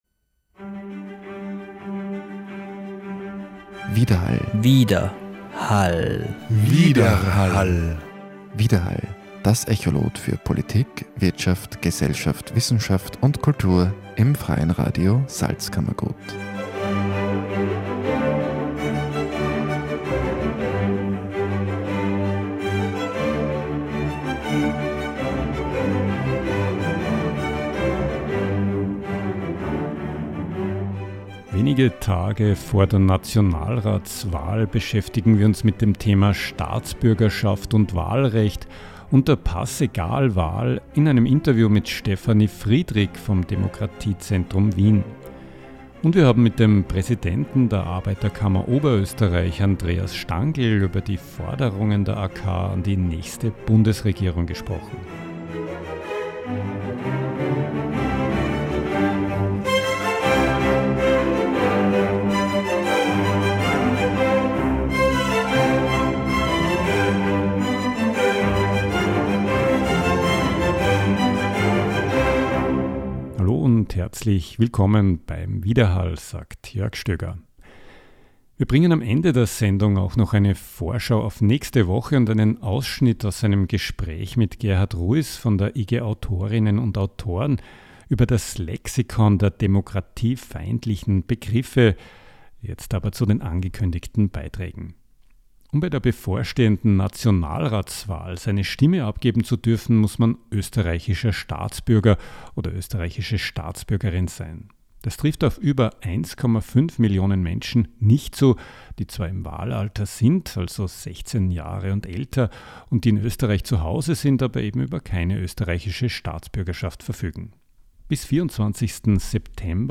Forderungen der AK an die nächste Bundesregierung Gespräch mit dem Präsidenten der Arbeiterkammer Oberösterreich über die Forderungen der AK an die nächste Bundesregierung. Themen sind unter anderem die Teuerungen etwa bei Mieten und Energie, die auseinander klaffende Einkommensschere sowie Arbeitslosigkeit.